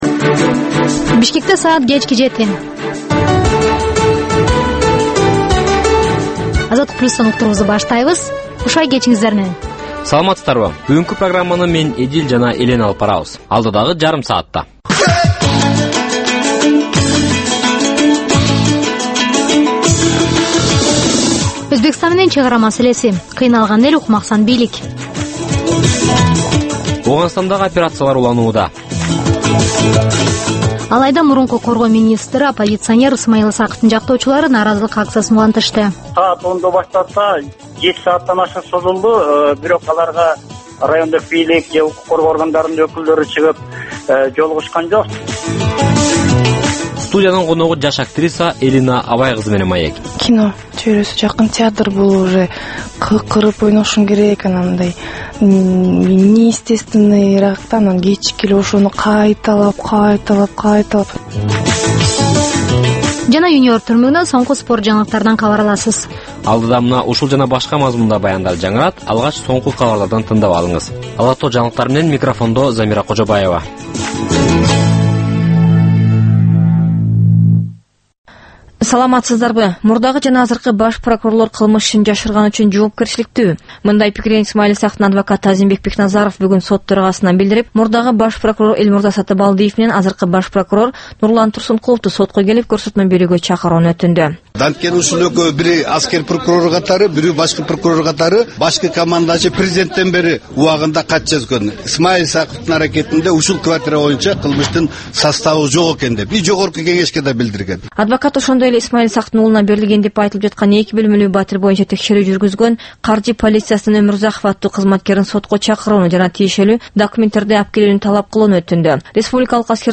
"Азаттык үналгысынын" кыргызстандык жаштарга арналган бул кечки алгачкы үналгы берүүсү жергиликтүү жана эл аралык кабарлардан, репортаж, маек, баян жана башка берүүлөрдөн турат. Ал Бишкек убактысы боюнча кечки саат 19:00дан 19:30га чейин обого чыгат.